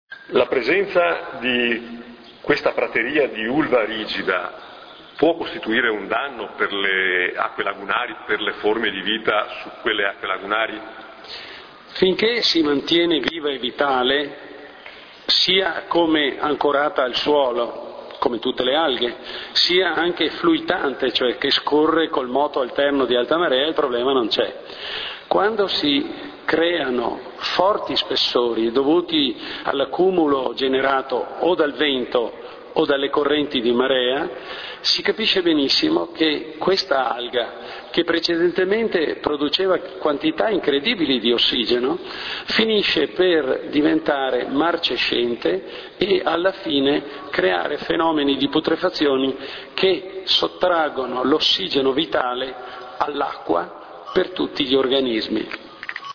L'intervista           [... attiva l'audio, file .mp3]